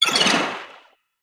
Sfx_creature_babypenguin_death_swim_01.ogg